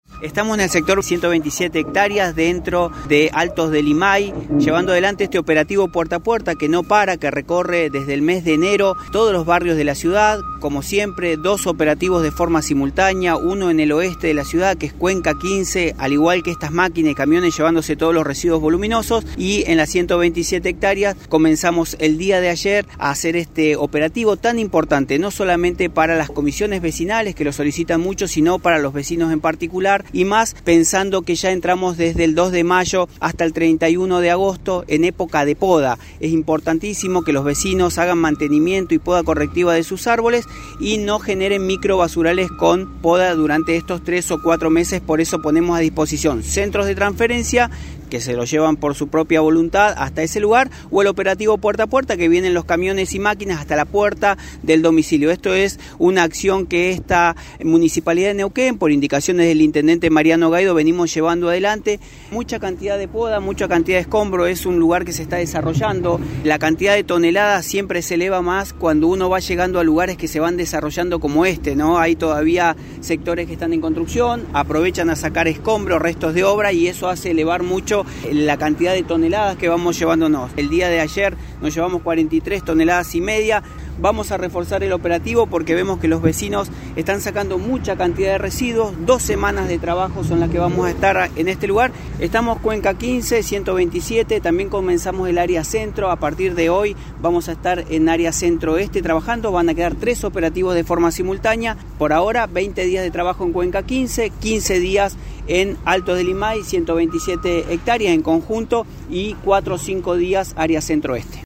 Cristian Haspert, subsecretario de Limpieza Urbana.
Cristian-Haspert-EDITADO-Puerta-a-Puerta-1.mp3